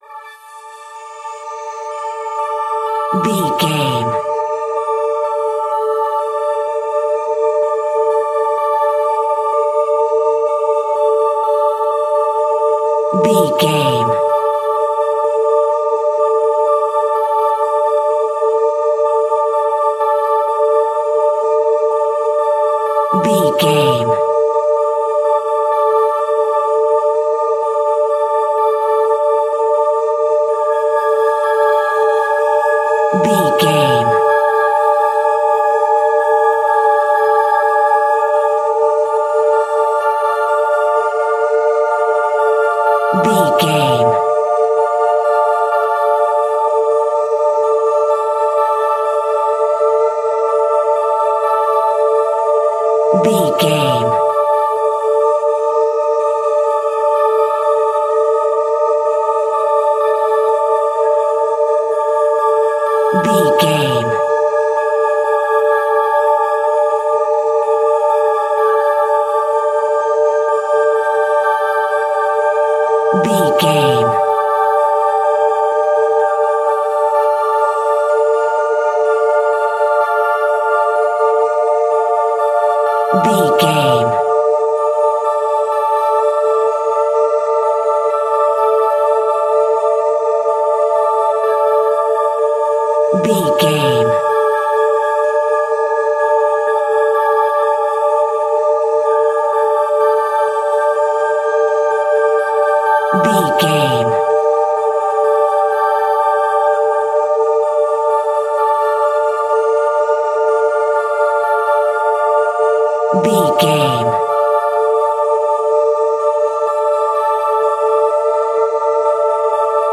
In-crescendo
Thriller
Aeolian/Minor
scary
ominous
dark
haunting
eerie
horror music
Horror Pads
horror piano
Horror Synths